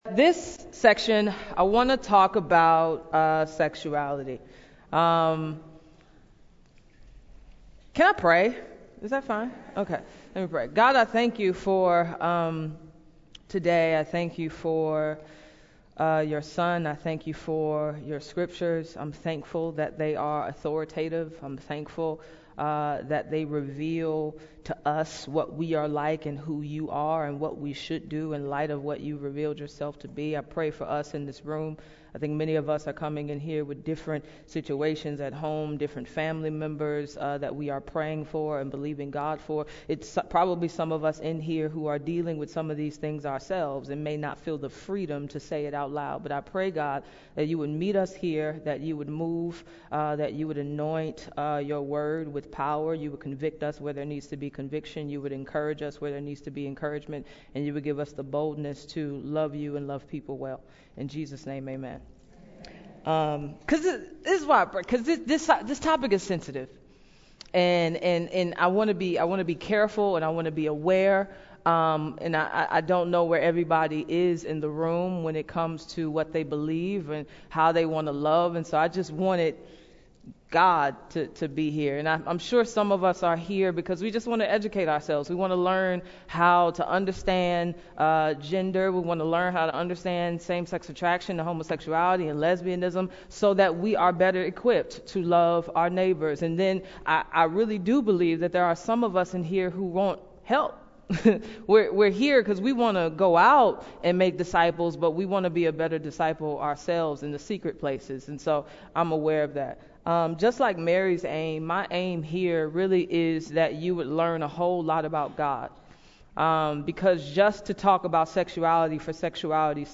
Firm Foundations in a Gender-Fluid World, Part 2 | True Woman '18 | Events | Revive Our Hearts